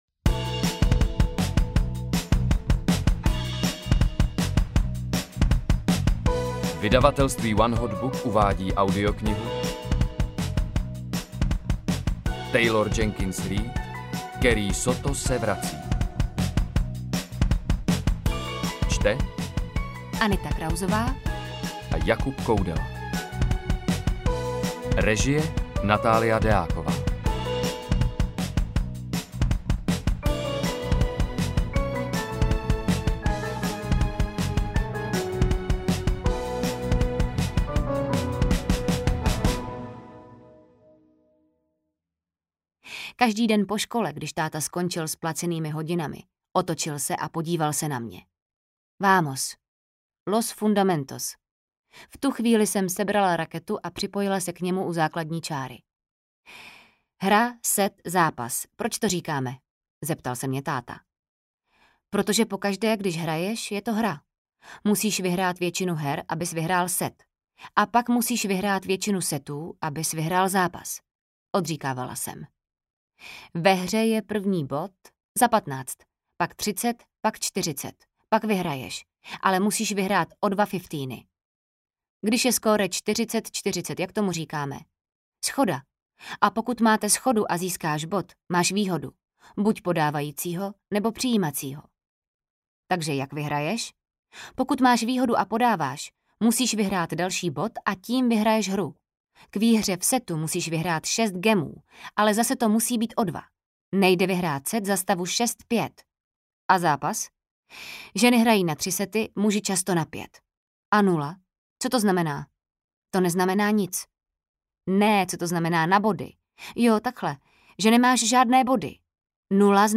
Carrie Soto se vrací audiokniha
Ukázka z knihy